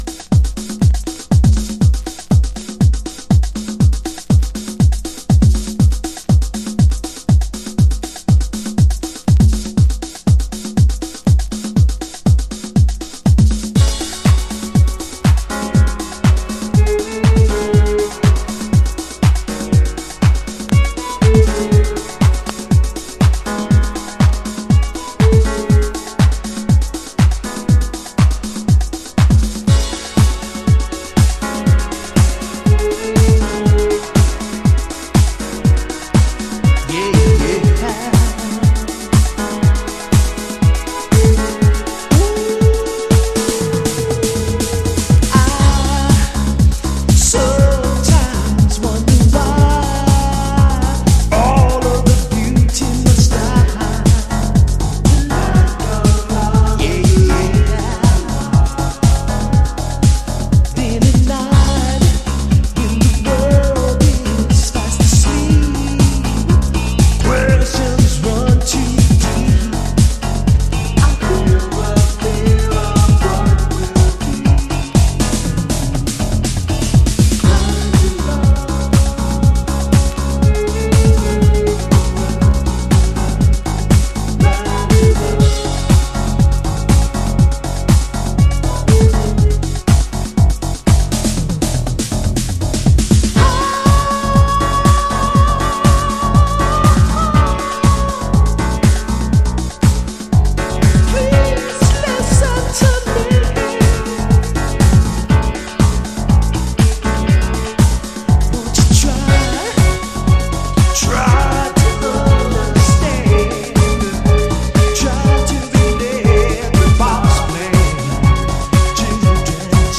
噎せ返るようなCHI-HOUSE。